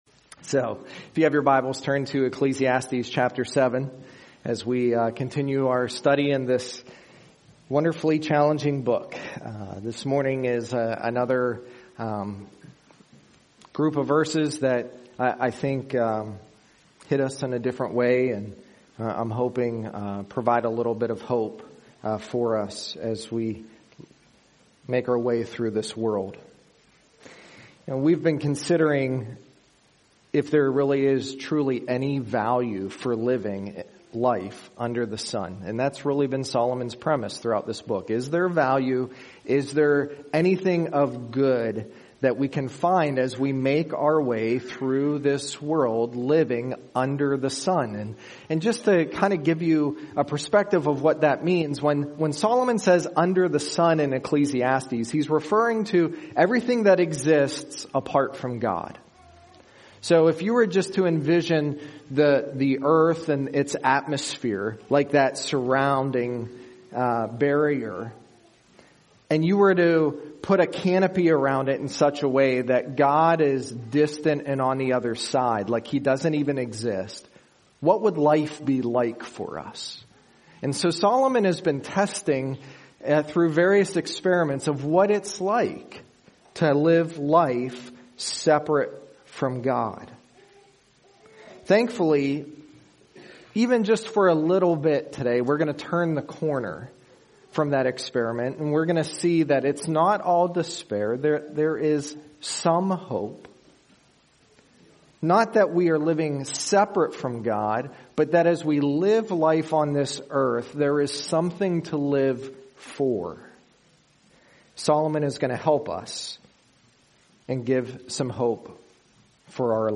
Sermons | North Annville Bible Church